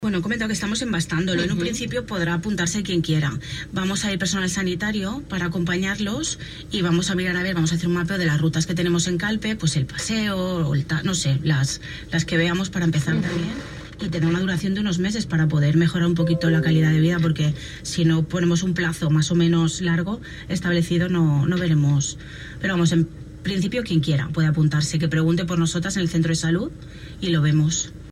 Entrevistas Sanidad